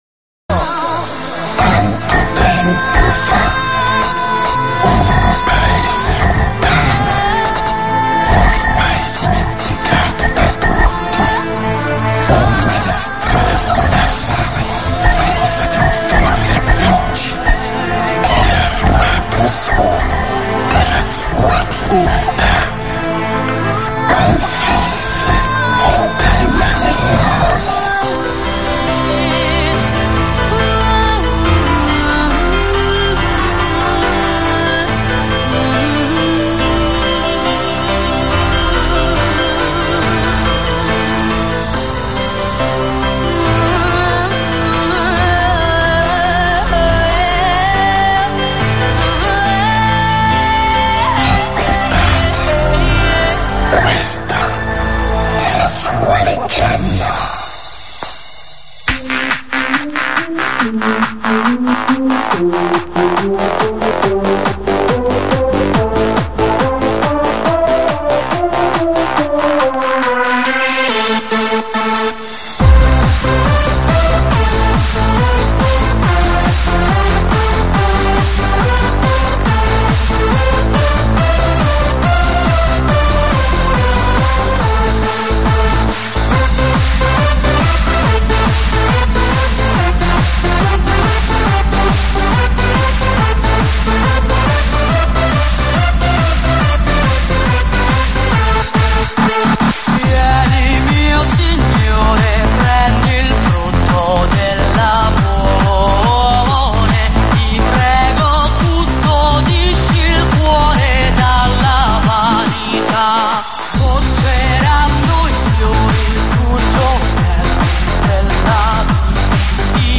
Italodance